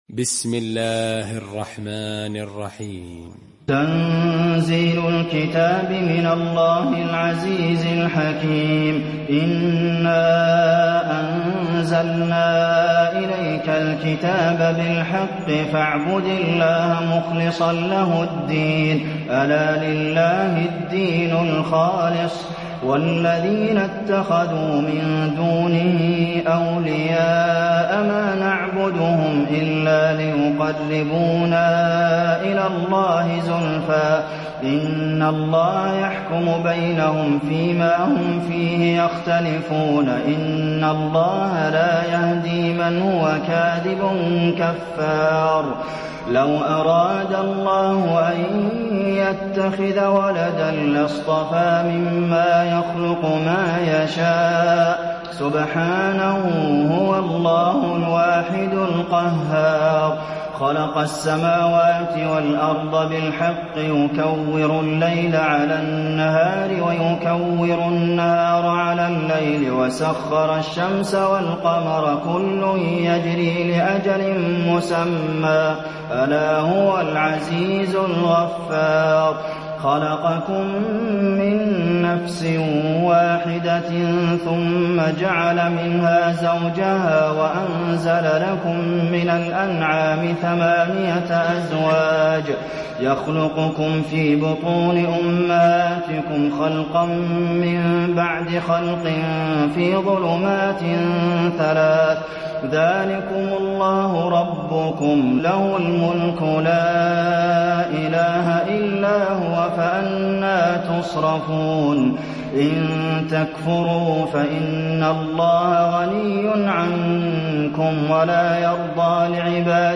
المكان: المسجد النبوي الزمر